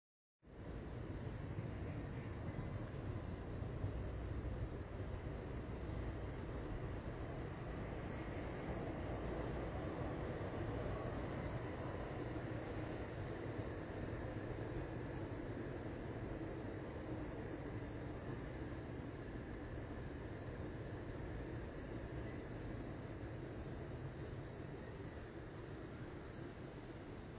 內湖區成功路四段323巷公園
均能音量: 46.3 dBA 最大音量: 61.1 dBA 地點類型: 公園 寧靜程度: 4分 (1分 – 非常不寧靜，5分 – 非常寧靜)
說明描述: 除了遠處傳來的車身外，這裡本身很寧靜 聲音類型: 車輛、鳥、鐵路交通、風